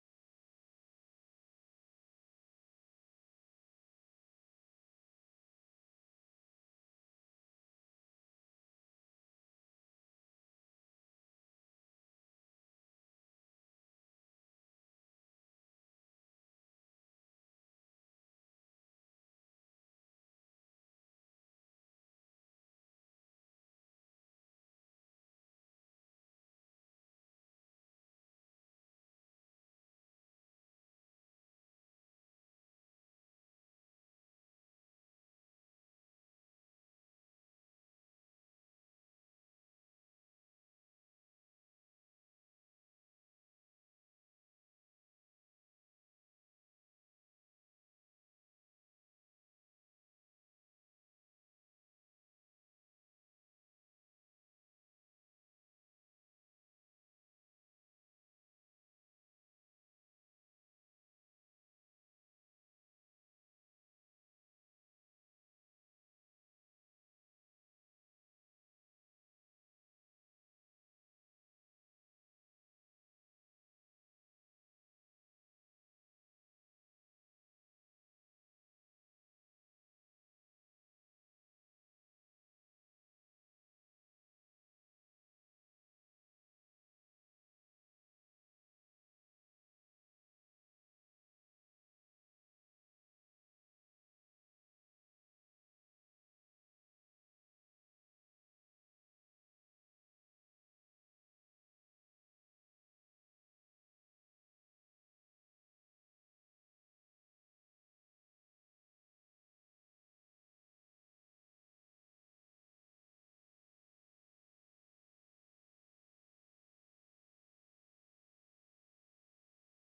This meeting will be conducted in a hybrid model with both in-person and virtual attendance.